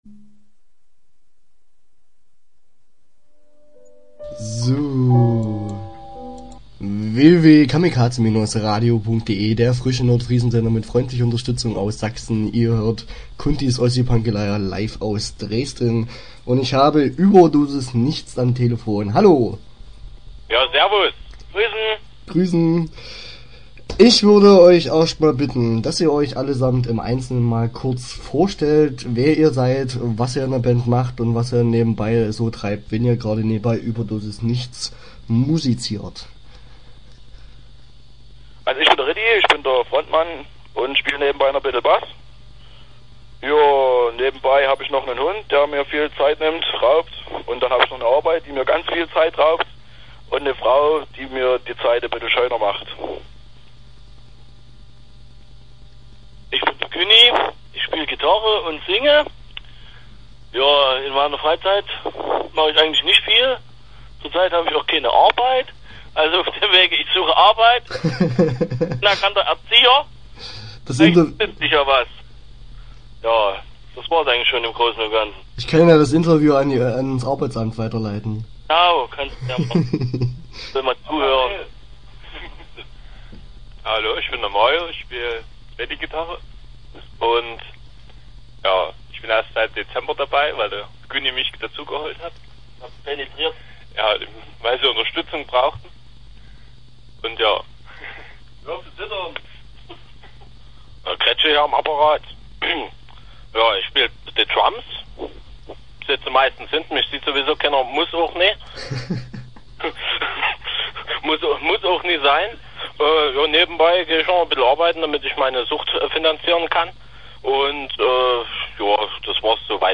Start » Interviews » Überdosisnichts